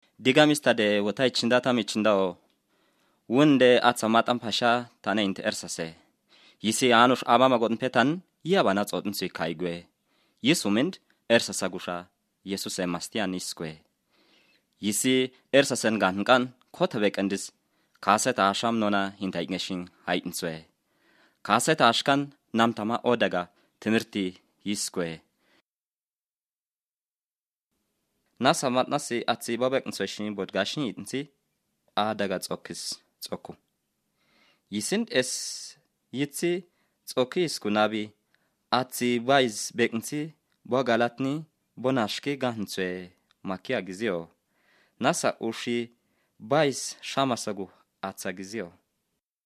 Here’s a recording in a mystery language.